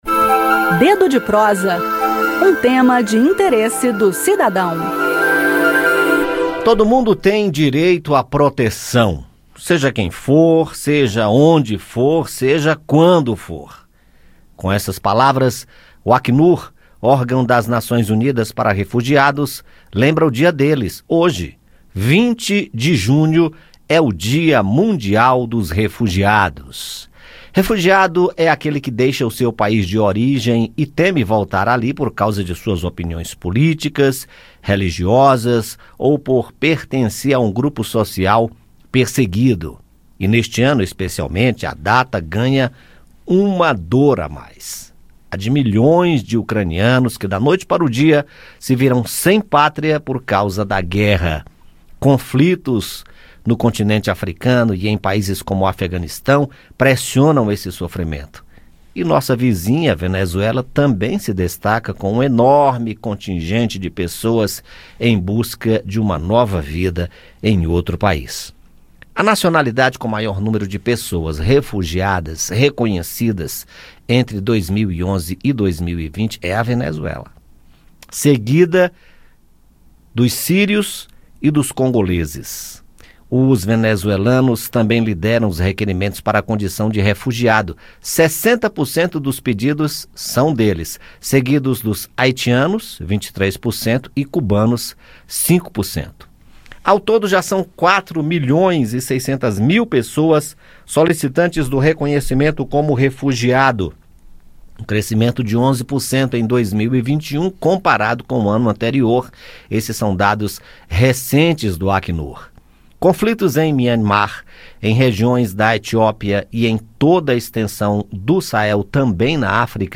bate-papo